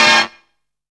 ACE STAB.wav